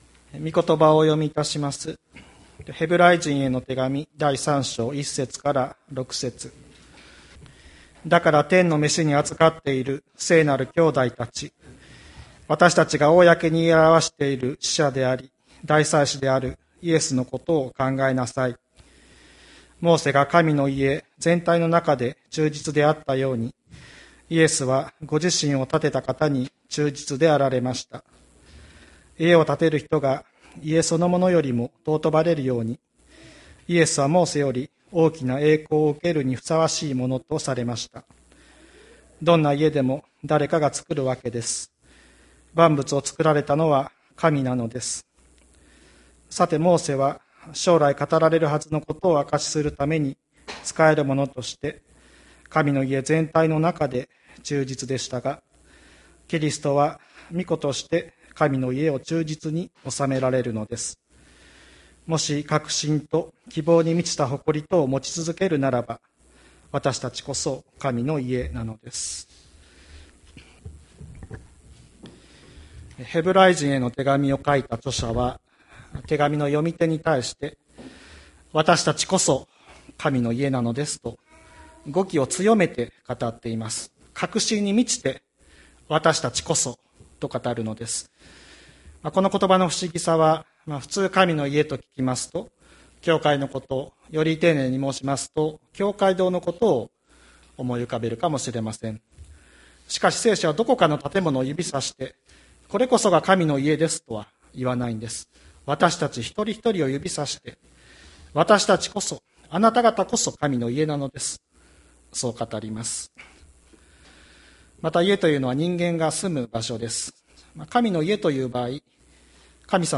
2021年11月07日朝の礼拝「わたしたちこそ神の家」吹田市千里山のキリスト教会
千里山教会 2021年11月07日の礼拝メッセージ。